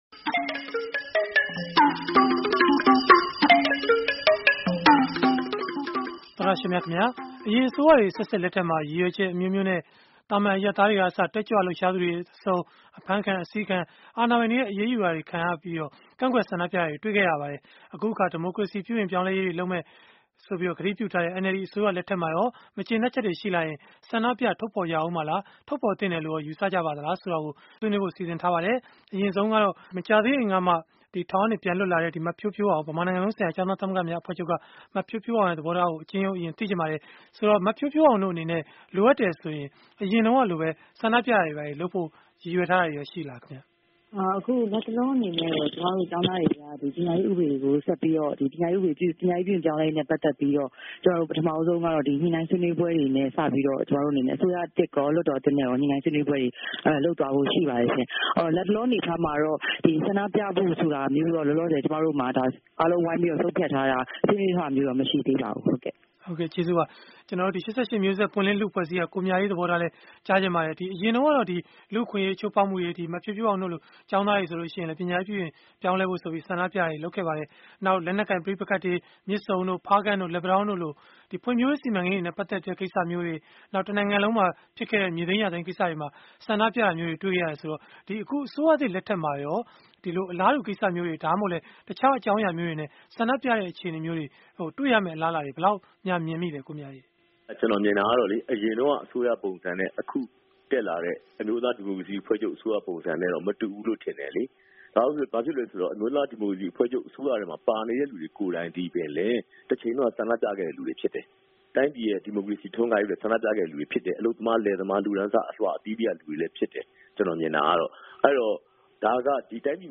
ဒီမိုကရေစီရေး ပြုပြင်ပြောင်းလဲမှုတွေလုပ်ဖို့ ကတိပြုထားတဲ့ NLD အစိုးရ လက်ထက်မှာရော မကျေနပ်ချက်တွေကို ဆန္ဒပြထုတ်ဖော်ကြဦးမှာလား၊ ထုတ်ဖော်သင့်တယ်လို့ရော ယူဆကြပါသလား၊ ဆိုတာကို အင်္ဂါနေ့ည တိုက်ရိုက်လေလှိုင်း အစီအစဉ်မှာ ဆွေးနွေးထားပါတယ်။